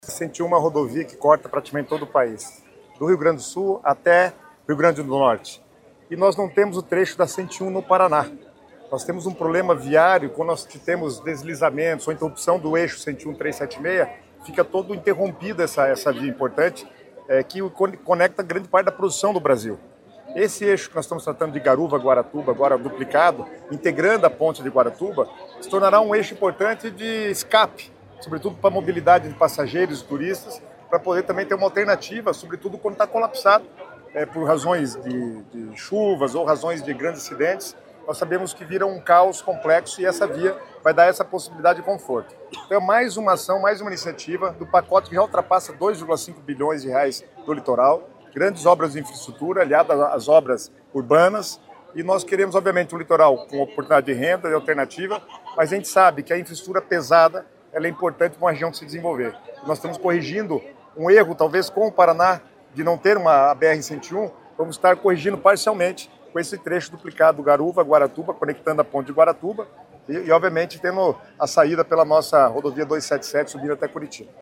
Sonora do secretário Estadual das Cidades, Guto Silva, sobre a autorização da duplicação da PR-412, em Guaratuba